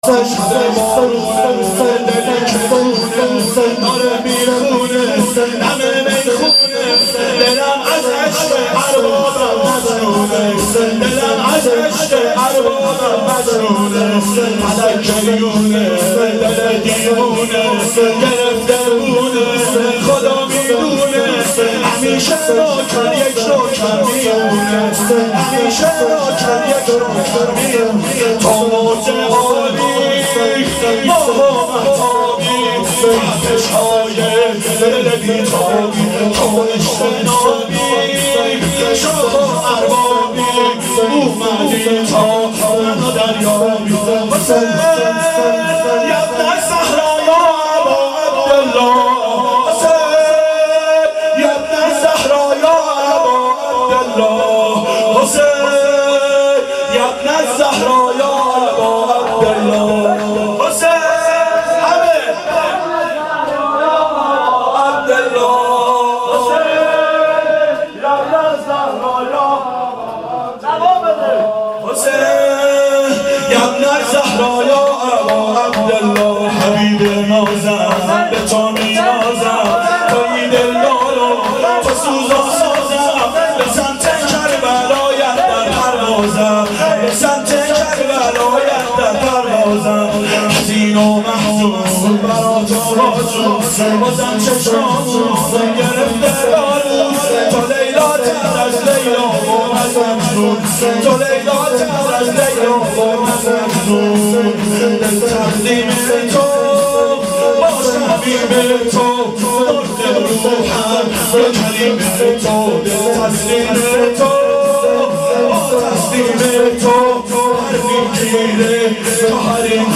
شب شهادت حضرت زهرا سلام الله علیها 1389 هیئت عاشقان اباالفضل علیه السلام